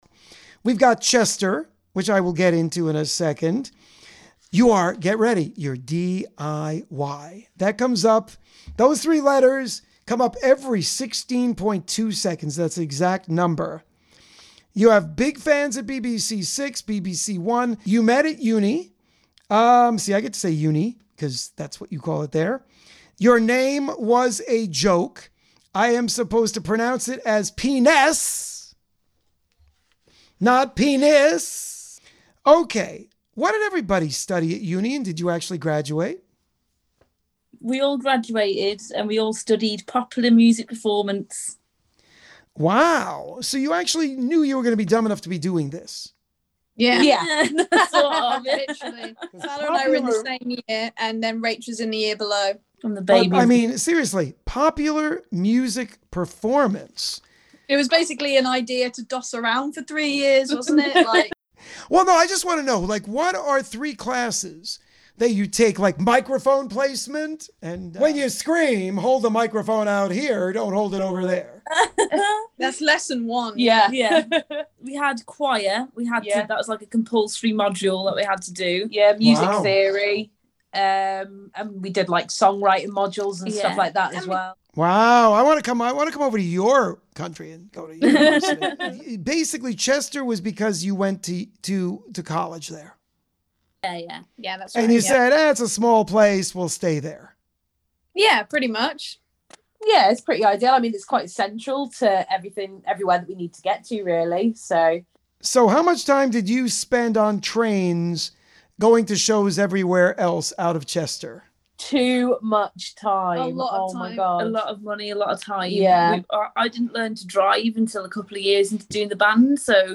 Peaness LISTEN TO THE INTERVIEW